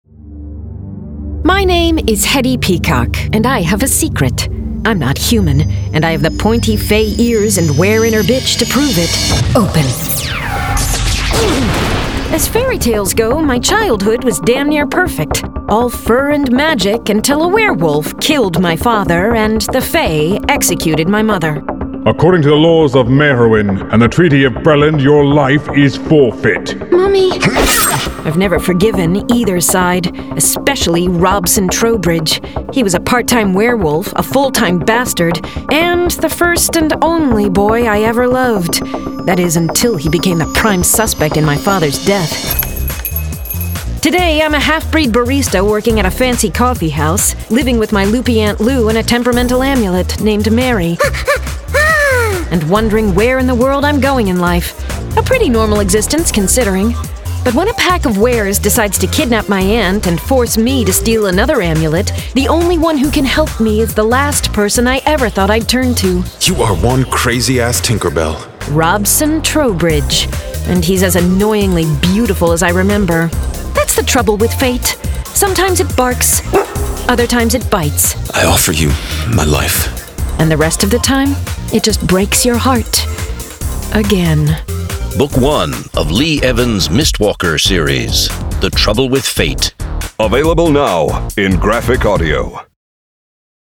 Full Cast. Cinematic Music. Sound Effects.
MYSTWALKER01-TRAILER.mp3